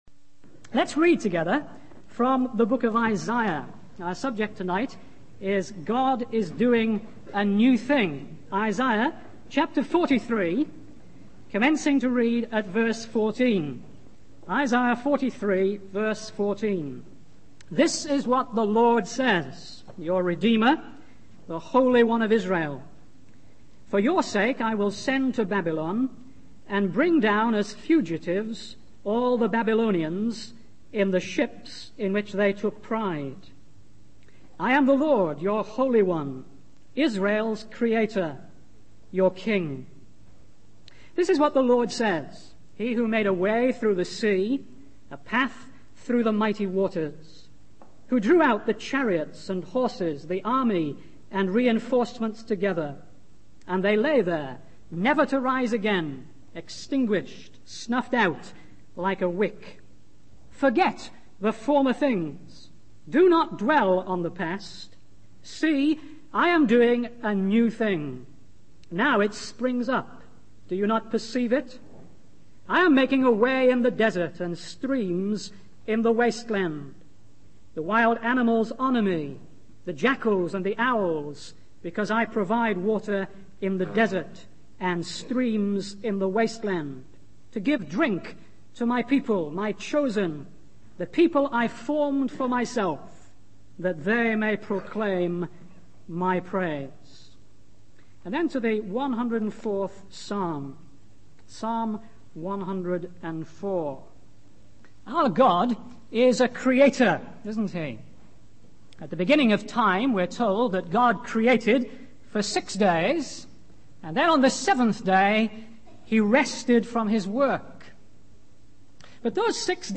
In this sermon, the speaker emphasizes the importance of remembering the Lord's greatness and power in our lives. He starts by reminding the audience of God's intervention at the Red Sea, where He saved Israel and destroyed the Egyptians.